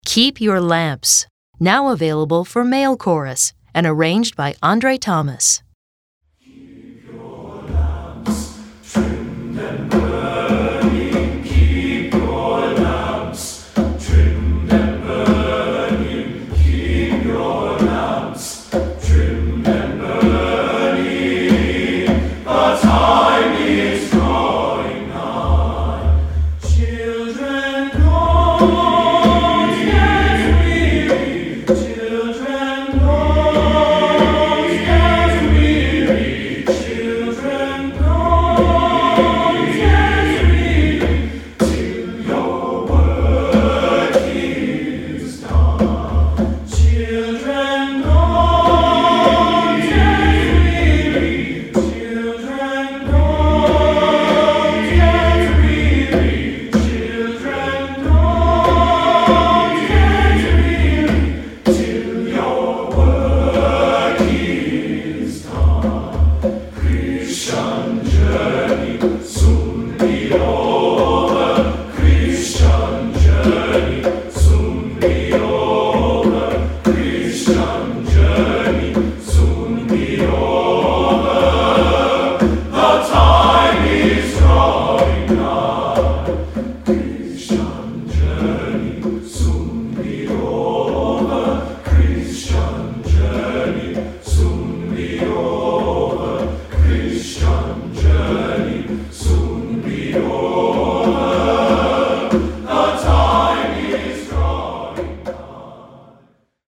Choral Male Chorus Spiritual
male chorus voicing with optional conga drums
Traditional Spiritual
TTBB A Cap